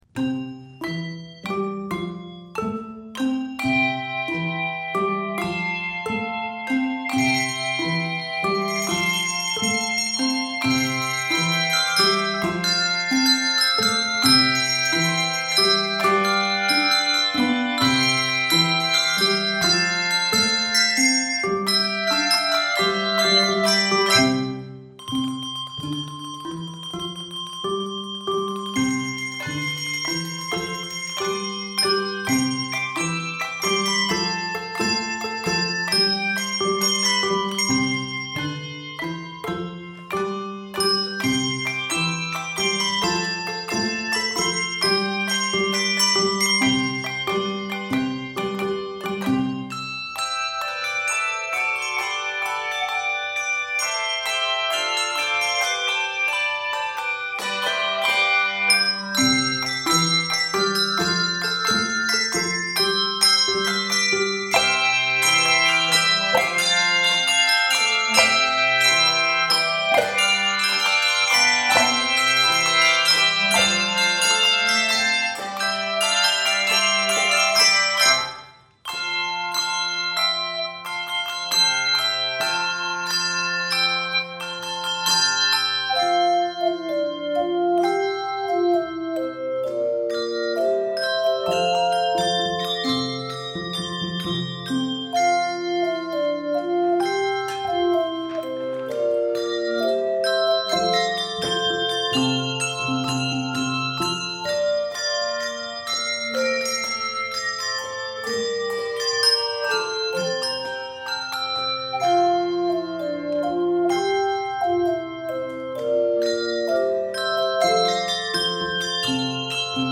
Here is a buoyant setting